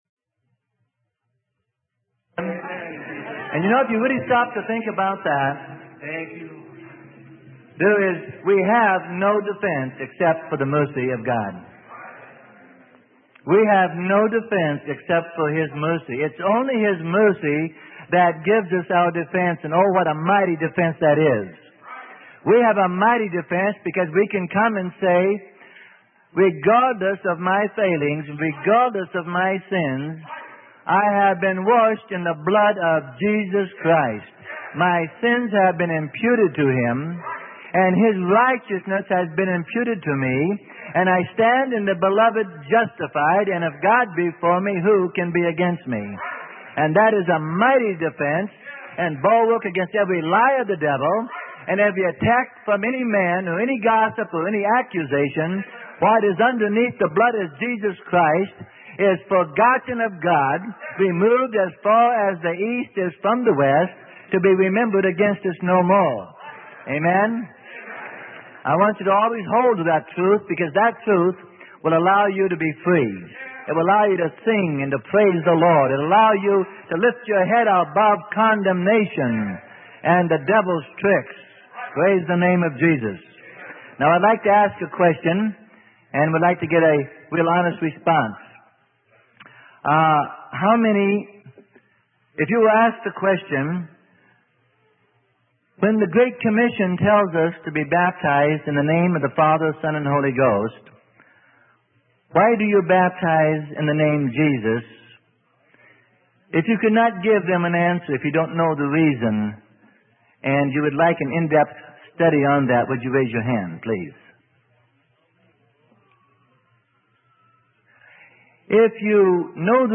Sermon: Water Baptism in the Name of Jesus - Why?